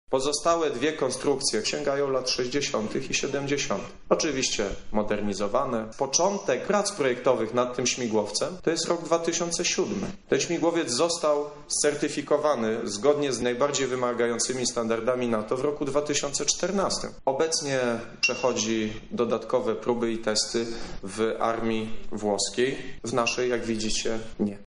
Prezentacja AW149